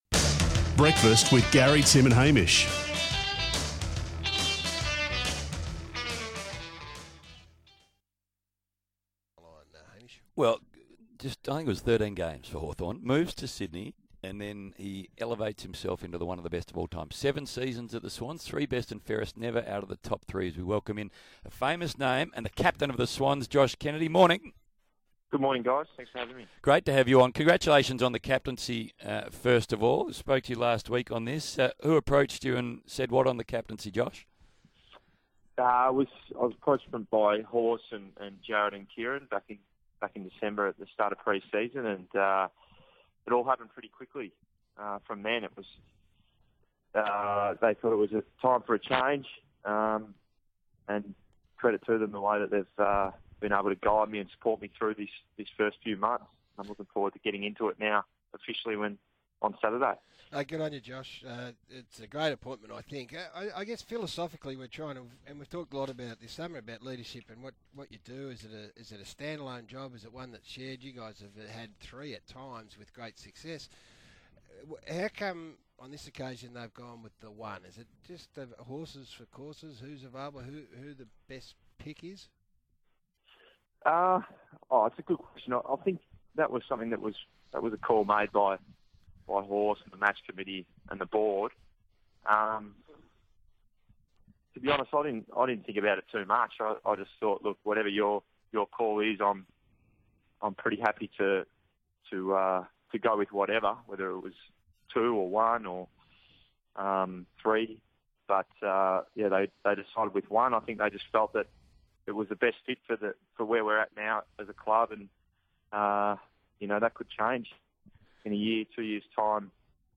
Captain Josh Kennedy speaks with the SEN Breakfast team on SEN membership day.